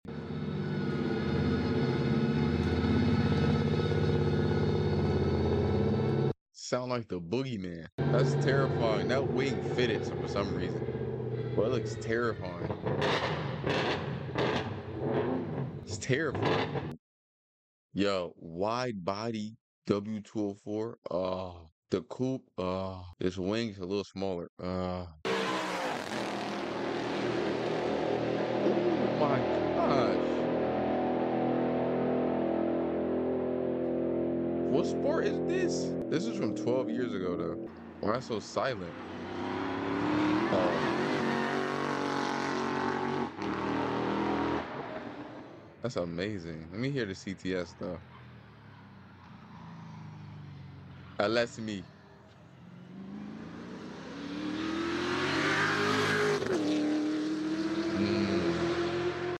Revisited the C63 Race Car sound effects free download